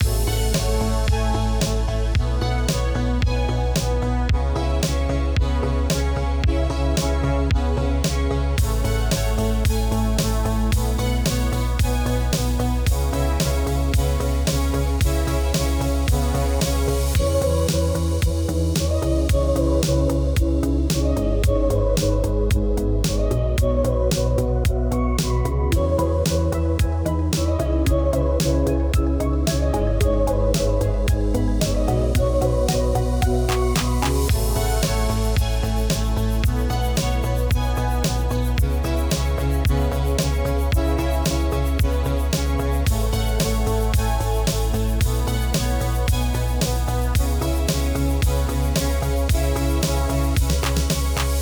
Synthwave_loop2.ogg